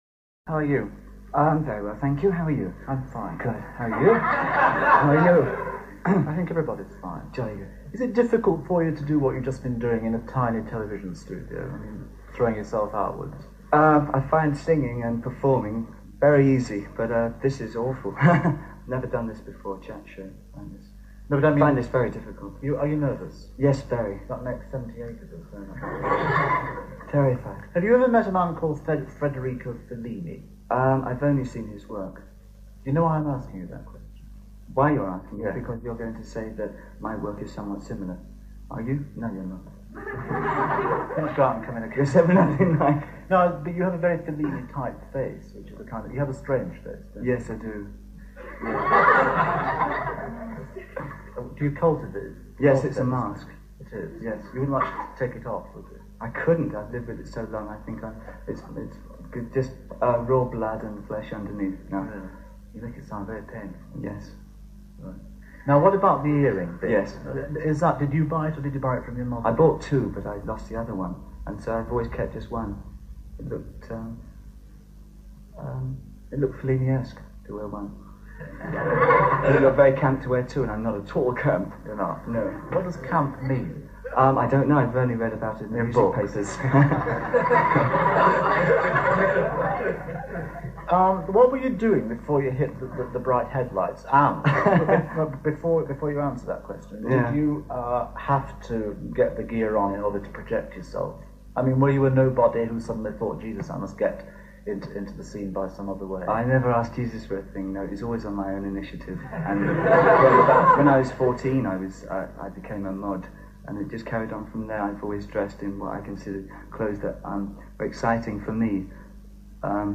Recorded and broadcast: 17th January 1973 Venue: London Weekend Television's South Bank Studios.
1973 Interview Russell Harty.mp3